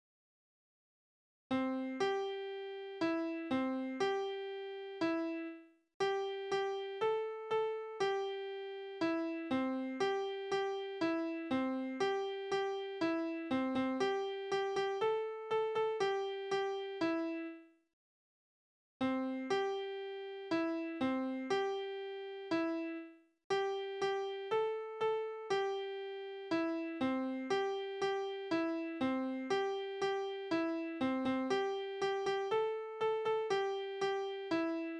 « 8346 » Laterne, Laterne, Sonne, Mond und Sterne Kindertänze: Die Laterne Laterne, Laterne, Sonne, Mond und Sterne, lösch aus das Licht, lösch aus das Licht, aber meine liebe Laterne nicht. Tonart: C-Dur Taktart: 4/4 Tonumfang: große Sexte Externe Links: Sprache: hochdeutsch eingesendet aus Demmin (1933) Fragen, Hinweise, Kritik?